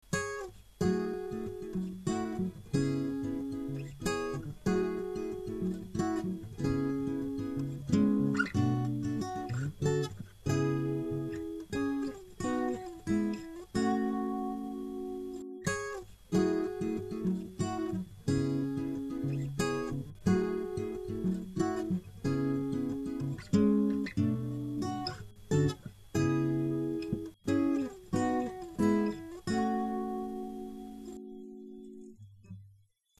Guitar arrangements